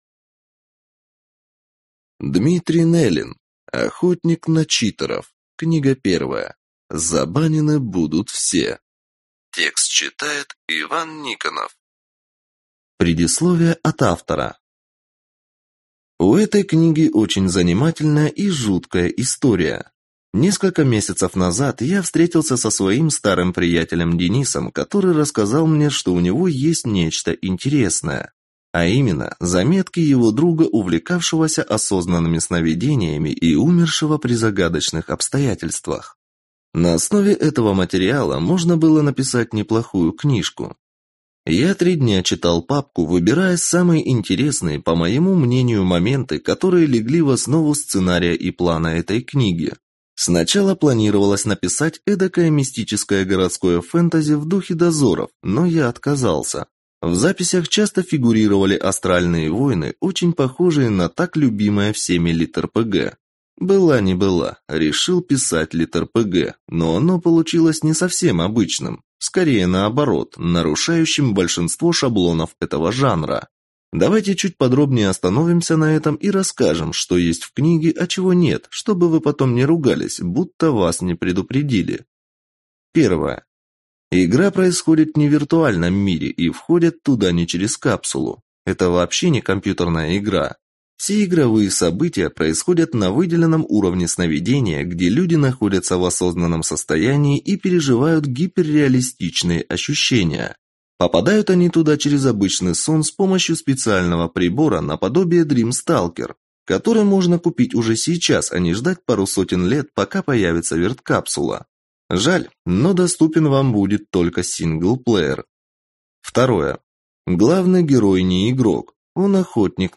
Аудиокнига Забанены будут все | Библиотека аудиокниг